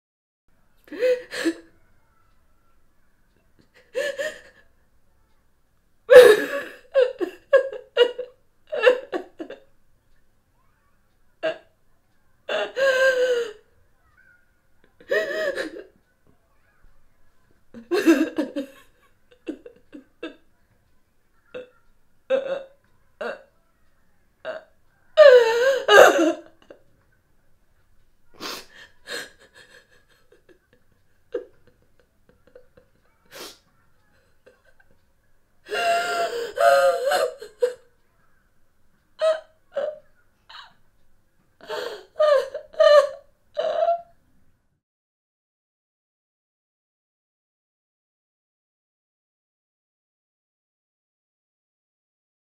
دانلود آهنگ گریه 3 از افکت صوتی انسان و موجودات زنده
جلوه های صوتی
دانلود صدای گریه 3 از ساعد نیوز با لینک مستقیم و کیفیت بالا